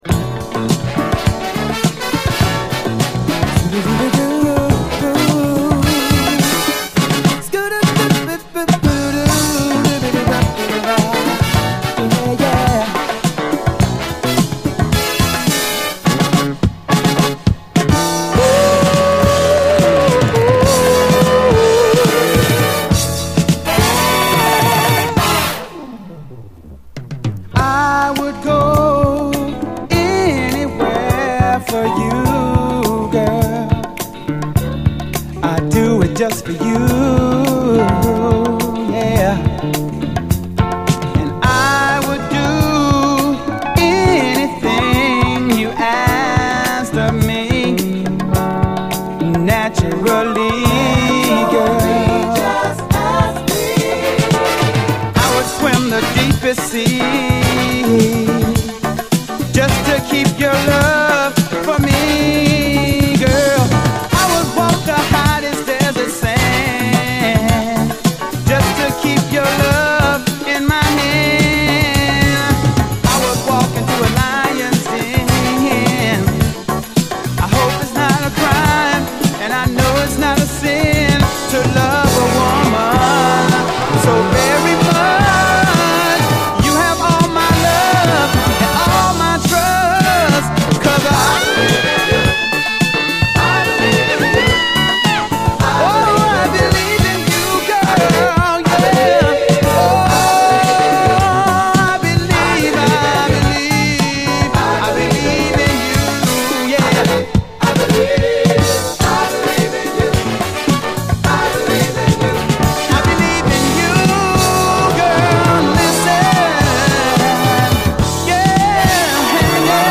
SOUL, 70's～ SOUL
爽快なサビとブラスの人気ミディアム・ソウル
ジャジーなムードでネタ感あるメロウ・グルーヴ